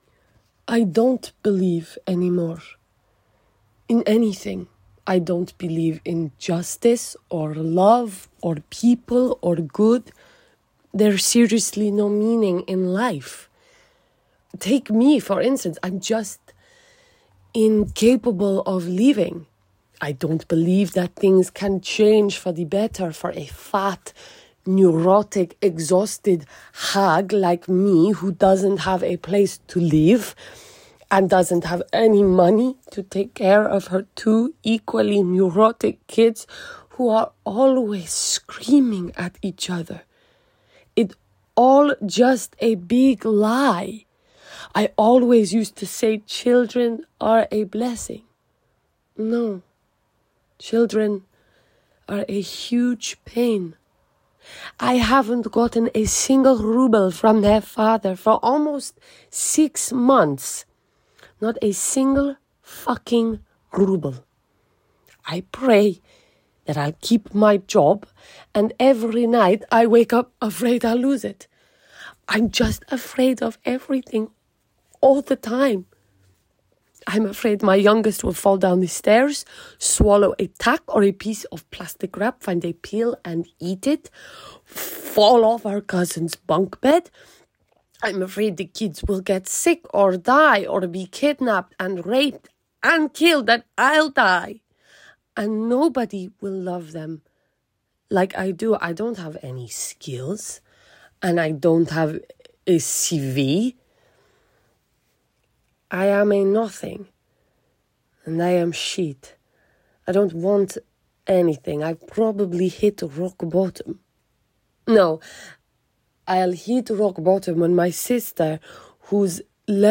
Eastern European